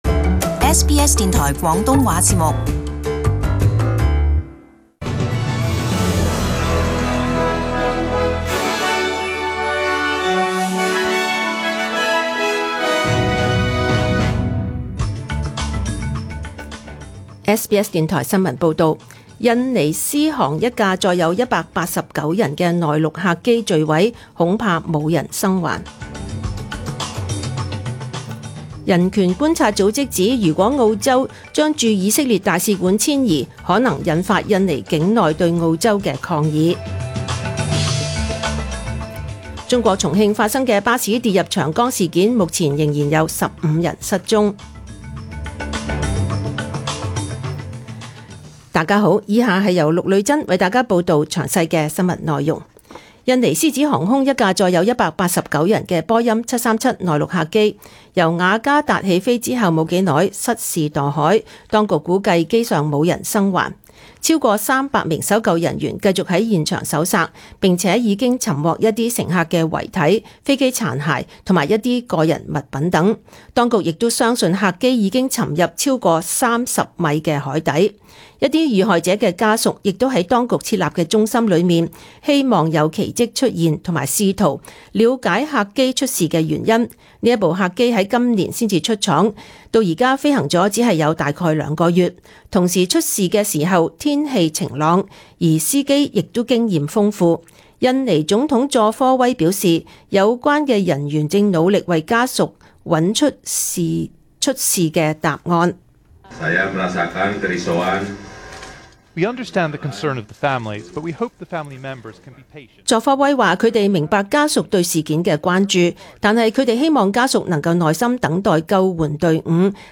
SBS中文新聞 （十月三十日）